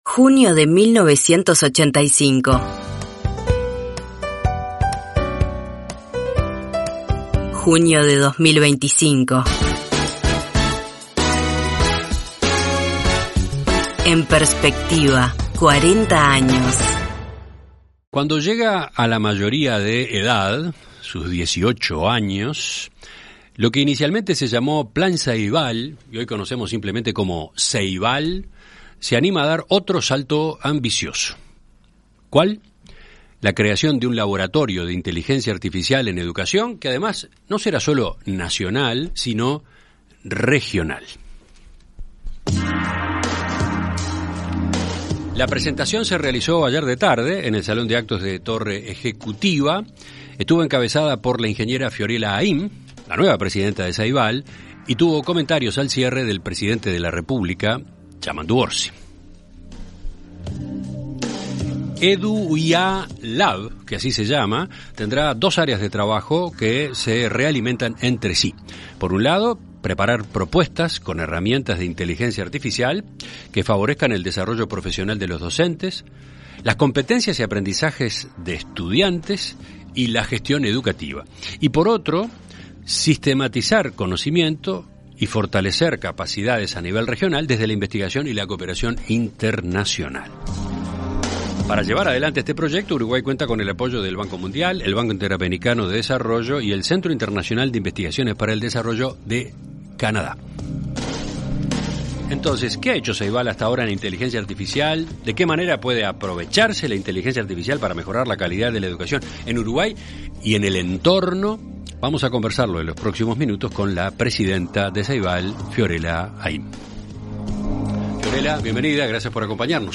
En Perspectiva Zona 1 – Entrevista Central: Fiorella Haim - Océano
Conversamos con la presidenta de Ceibal, Fiorella Haim.